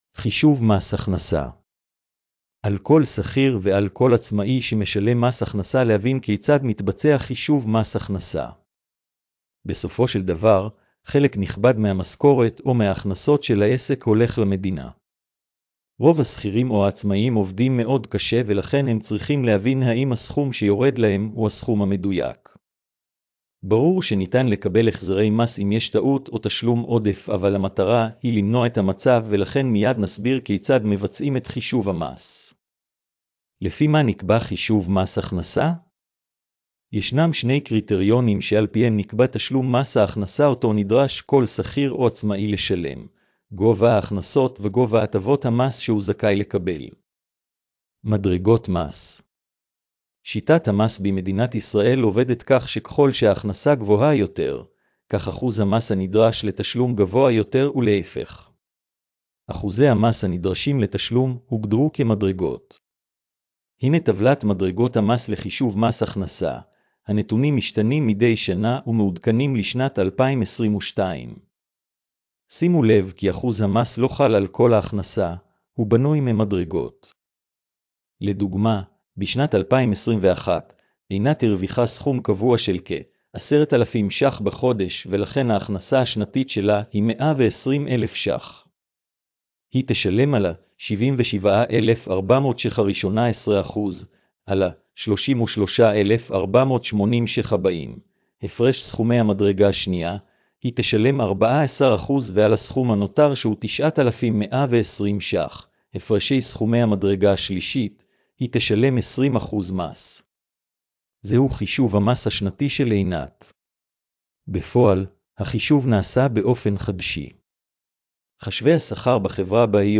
הקראת המאמר לבעלי מוגבלויות: